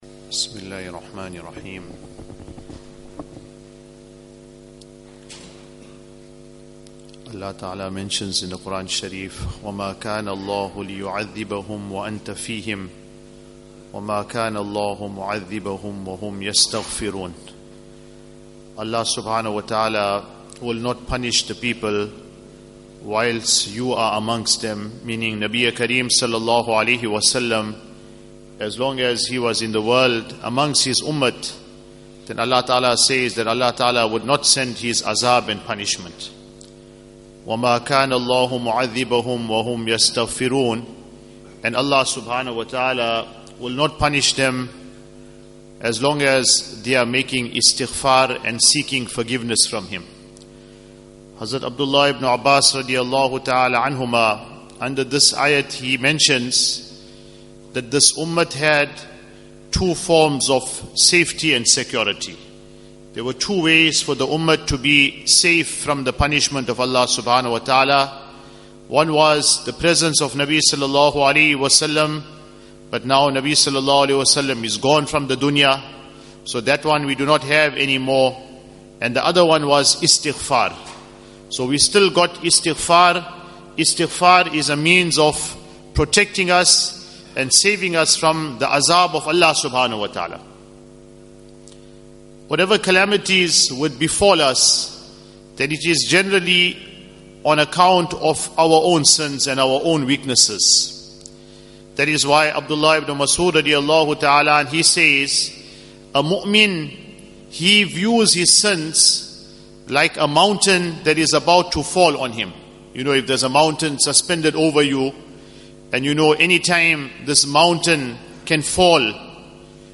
Morning Discourses